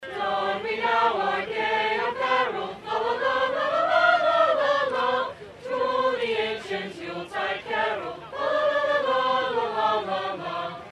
The tree-lighting ceremony also featured holiday music from the Manhattan High School Chamber Choir.